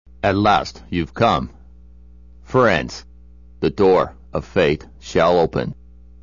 If you thought Resident Evil was the apex of bad voice acting, think again.